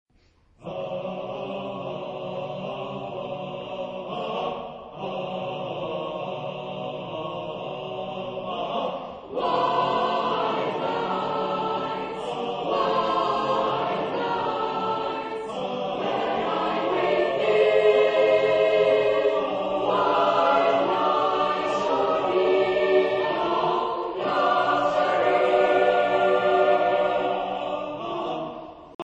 Genre-Style-Forme : Cycle ; Pièce vocale ; Profane
Type de choeur : SSAATTBB  (8 voix mixtes )
Tonalité : libre